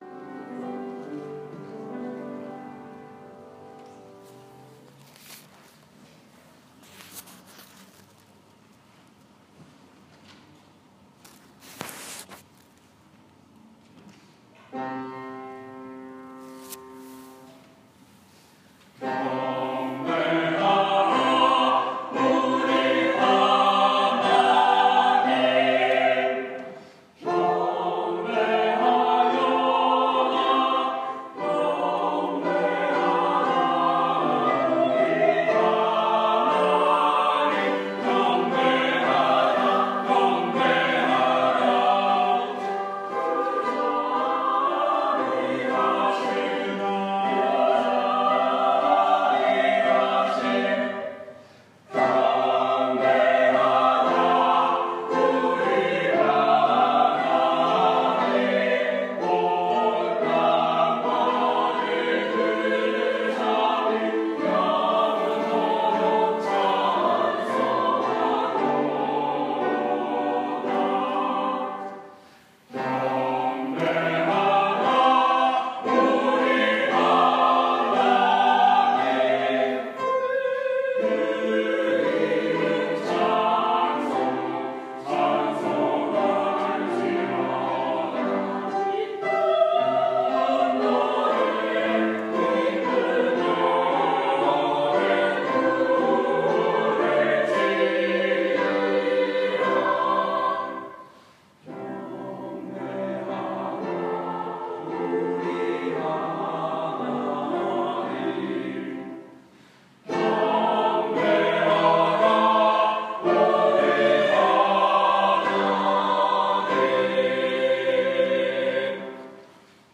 5월 22일 주일 찬양대 찬양( ‘경배하라 우리 하나님’ J. C.Rinck)